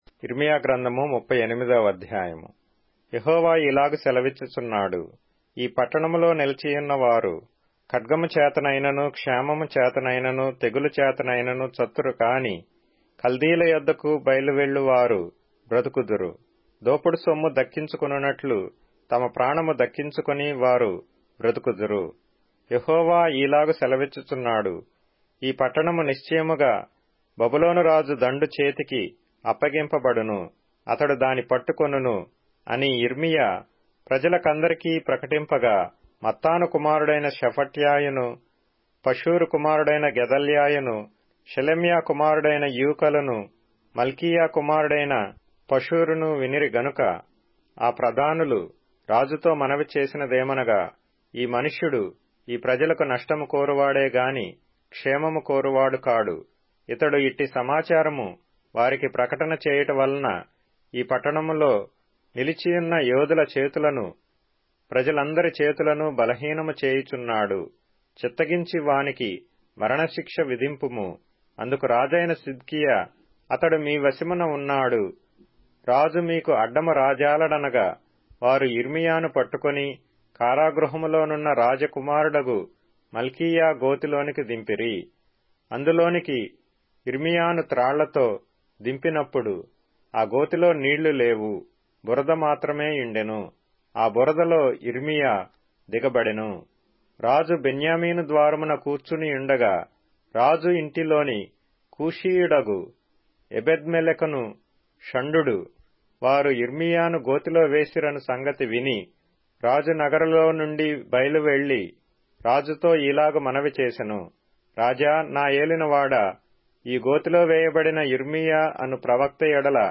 Telugu Audio Bible - Jeremiah 49 in Akjv bible version